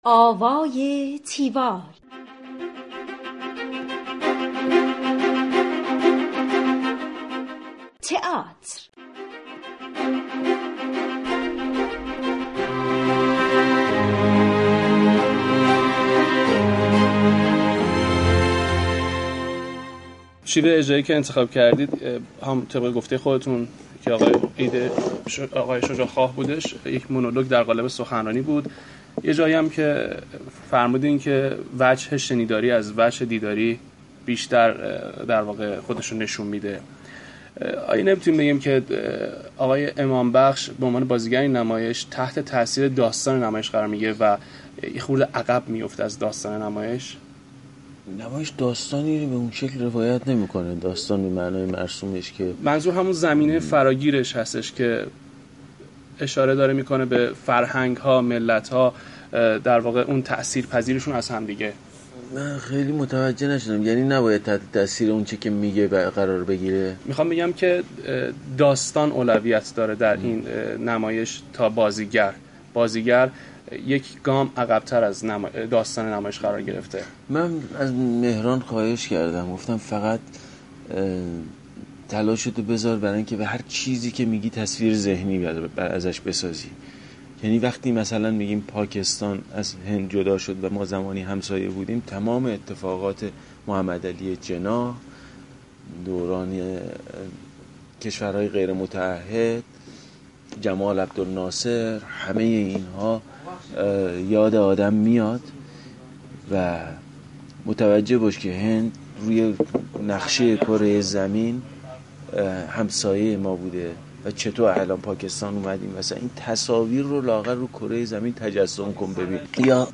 گفتگوی تیوال با رحیم نوروزی ( بخش دوم)